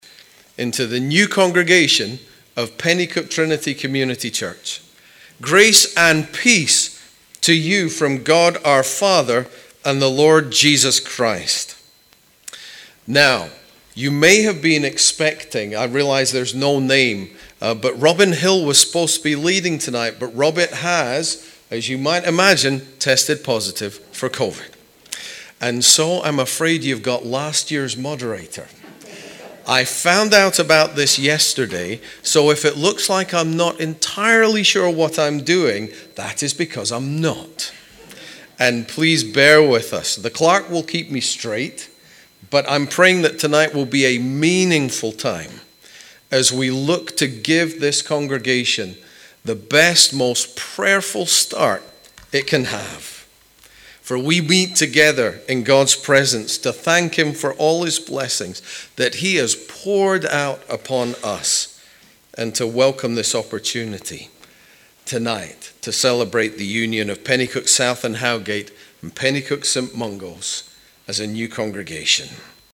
Penicuik Trinity Community Church was led by Presbytery on Thursday 7 April.
welcome.mp3